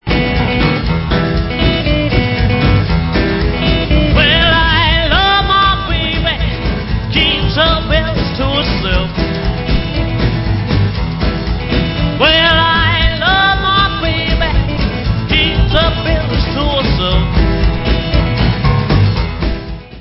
sledovat novinky v oddělení Rock & Roll